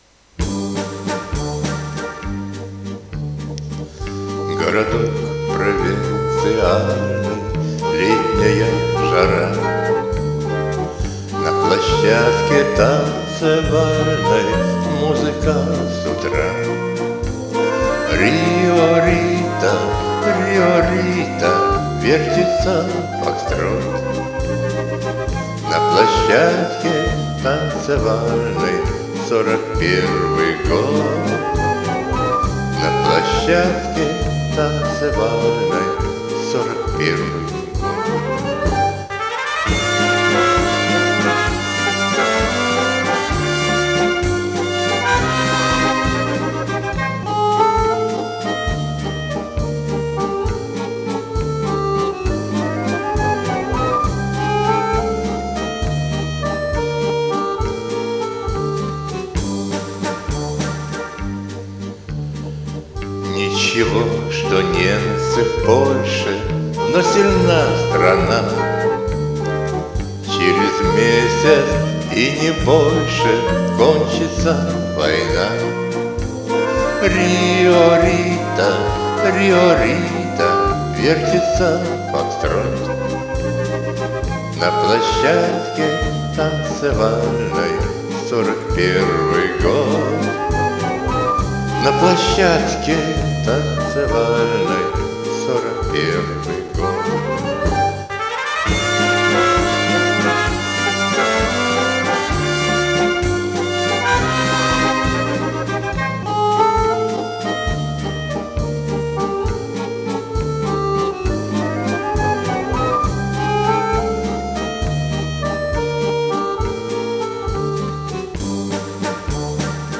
Комментарий соперника: Предвоенный Вальс 1940 года!